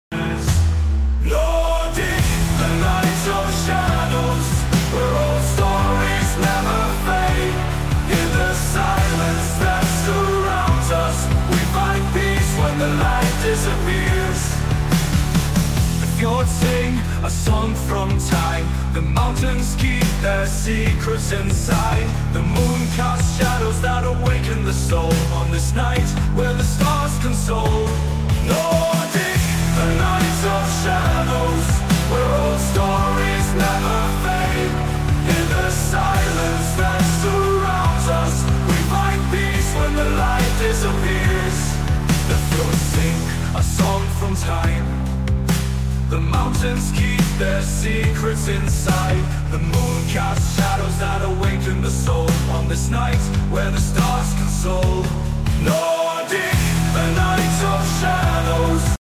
Nordic Viking